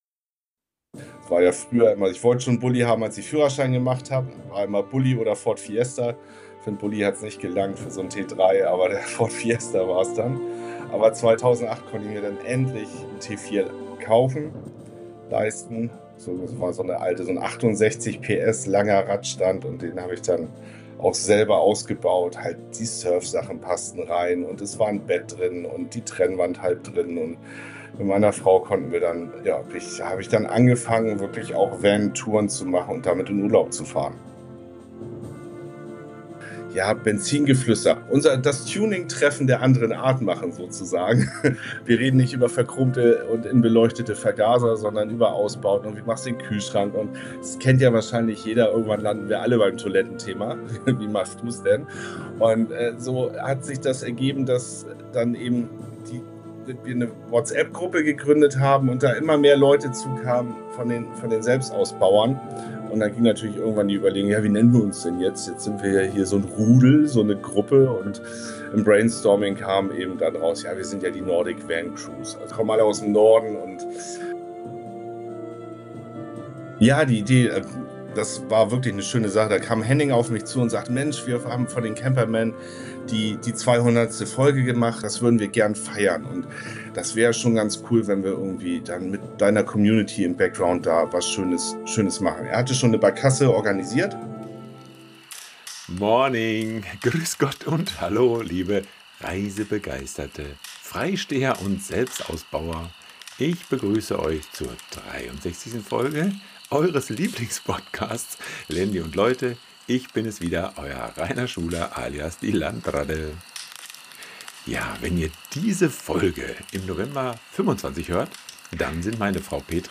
Heute habe ich einen Gast im Studio, der mit seiner Familie auch viel reist, dass aber im selbst ausgebauten Sprinter von 7m Länge.